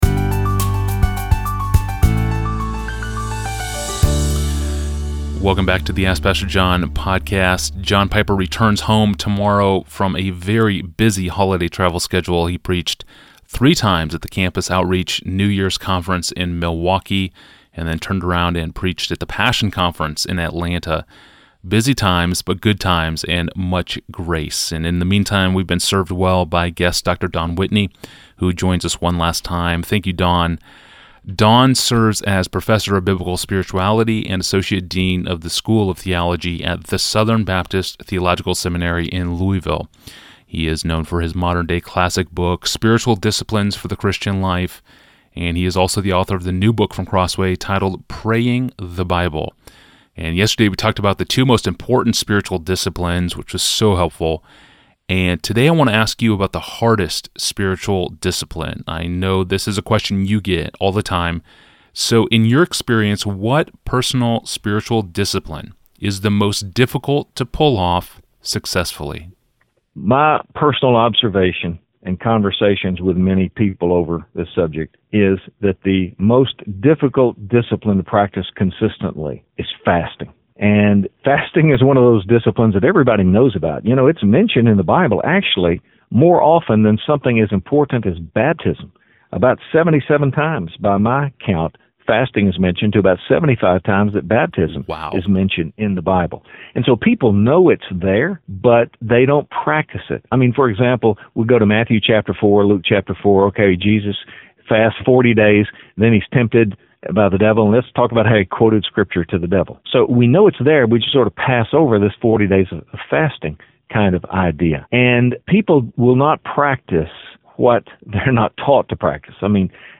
(I’ve included a teaser quotation from each interview).